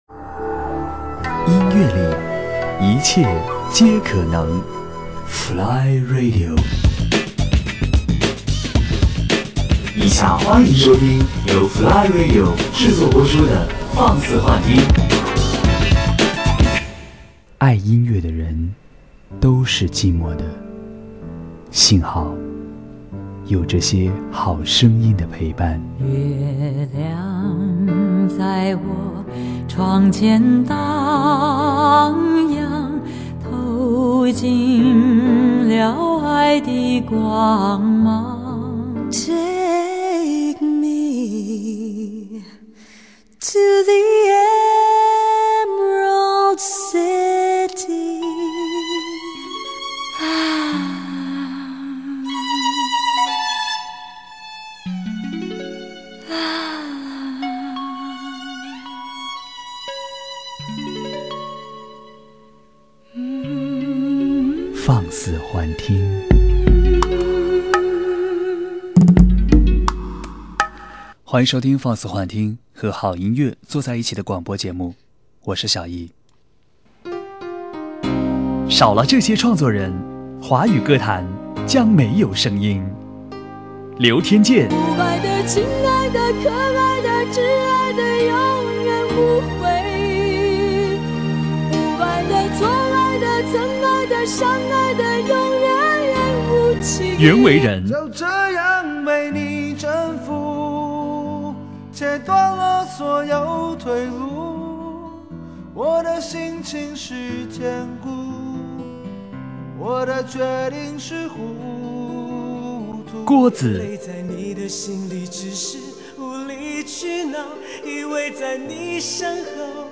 节目类型：音乐文化专栏